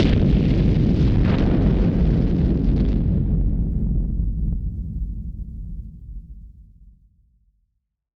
BF_HitSplosionB-06.wav